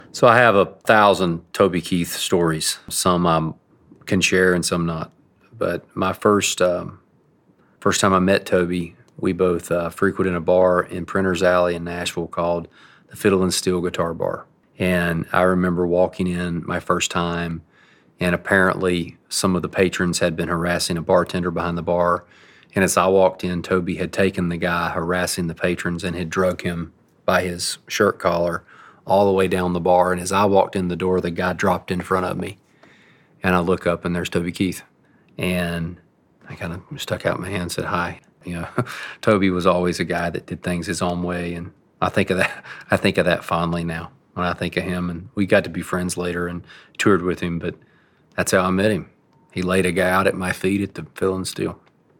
Eric Church recalls the moment he met Toby Keith.